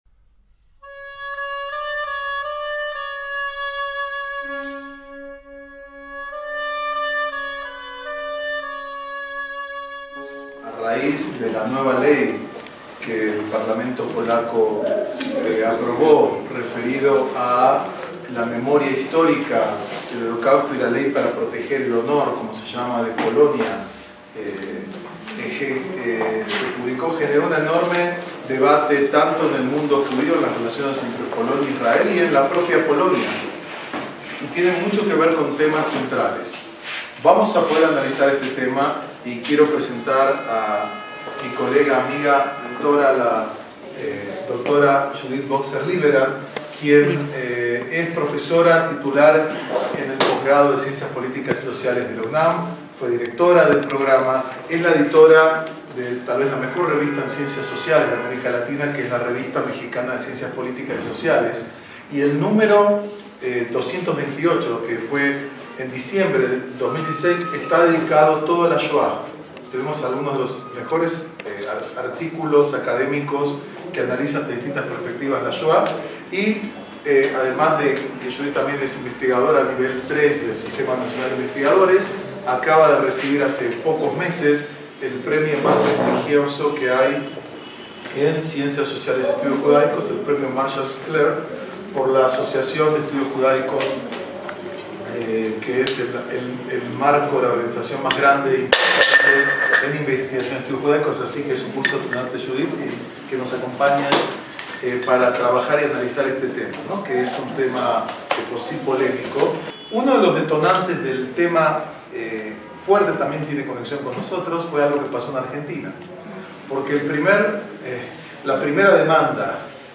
ACTOS EN DIRECTO